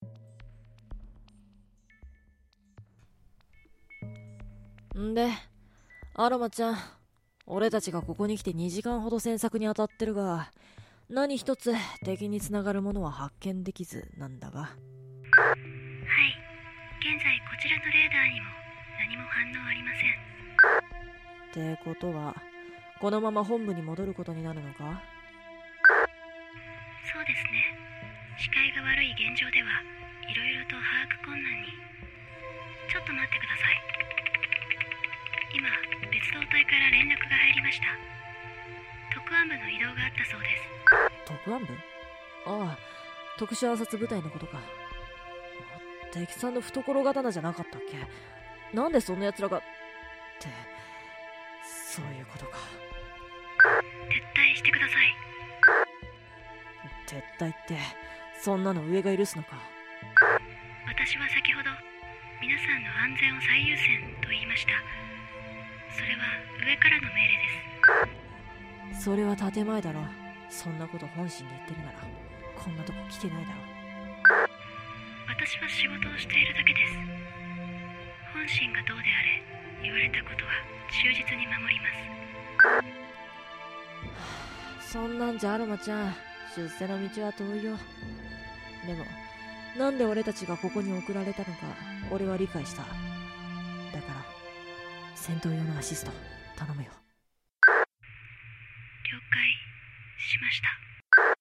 オペレーター
青年